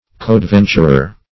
Coadventurer \Co`ad*ven"tur*er\, n. A fellow adventurer.